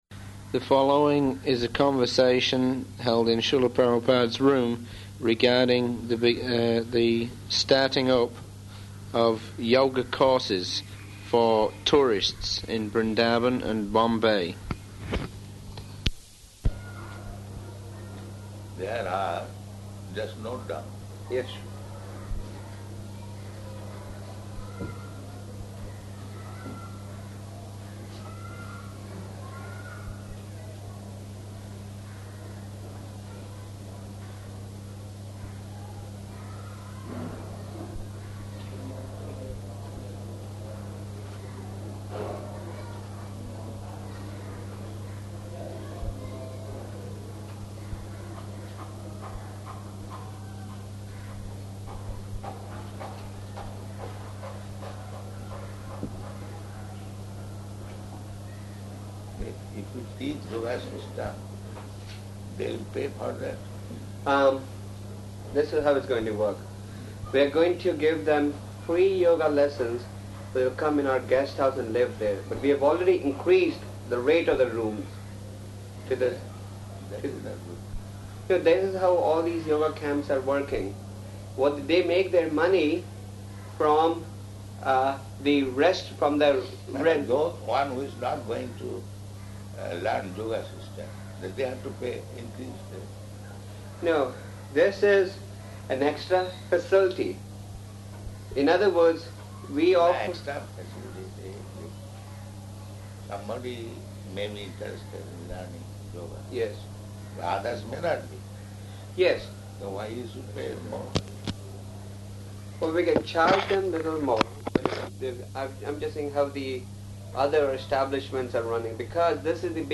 Room Conversation
Type: Conversation
Location: Bombay